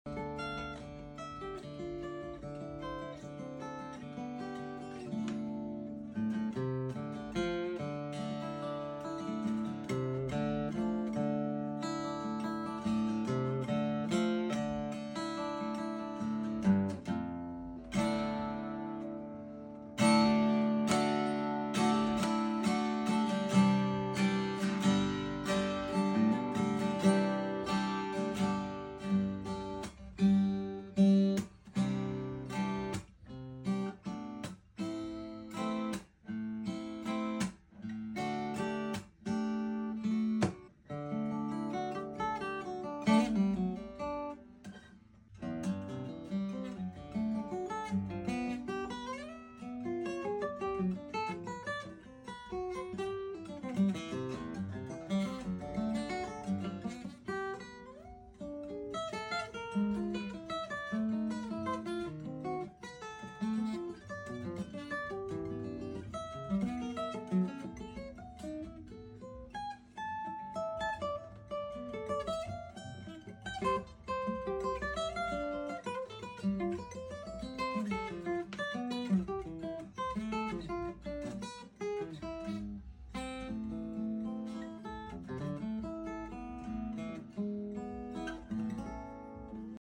Taylor Big Baby acoustic guitar sound effects free download
Taylor Big Baby acoustic guitar sound sample after Setup Standard